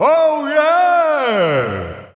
Jimmy T's voice from the official Japanese site for WarioWare: Move It!
WWMI_JP_Site_Jimmy_T_Voice.wav